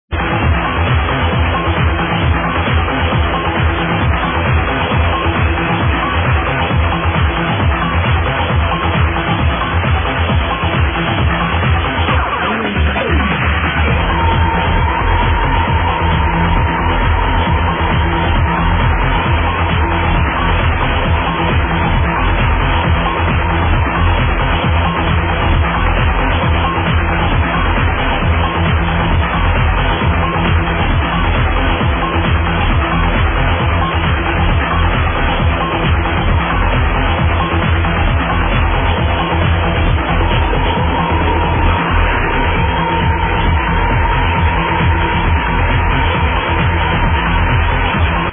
The quality is waaaay too poor